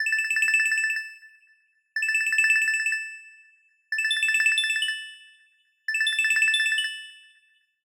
ringtone_minimal.ogg